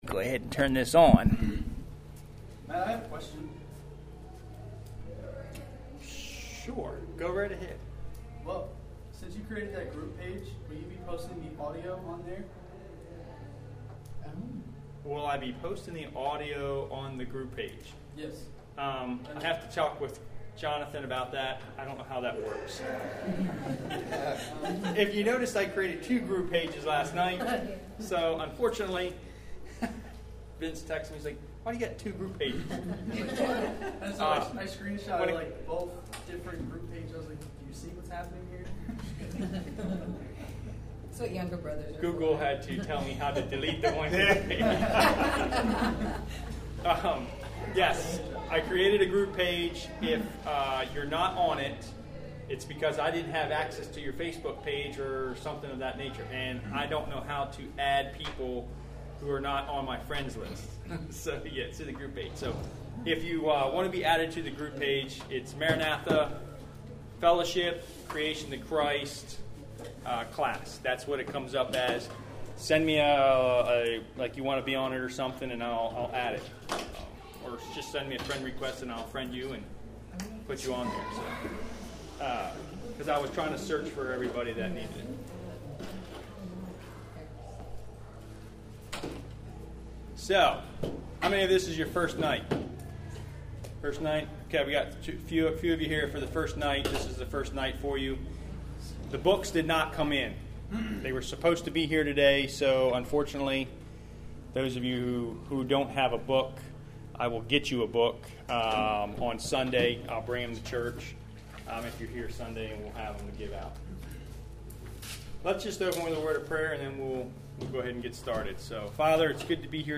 Lesson 3